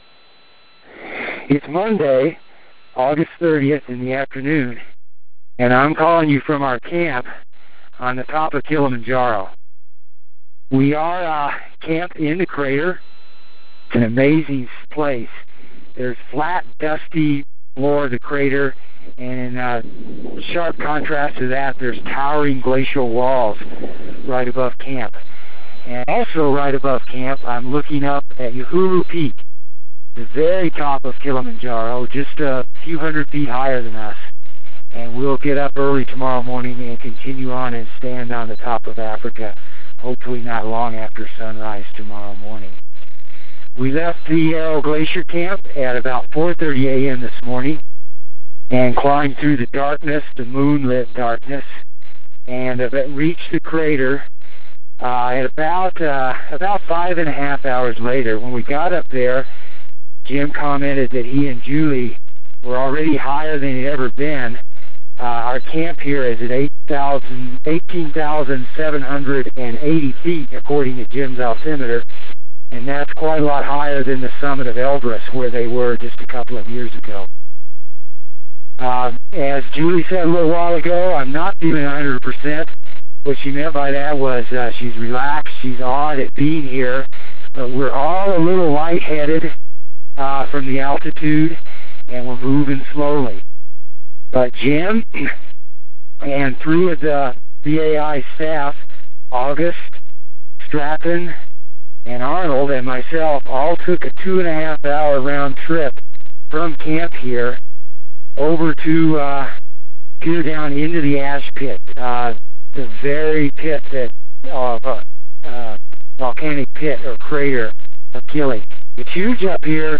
August 30 – Camped in the Crater of Kilimanjaro